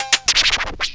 COWBELL SCR.wav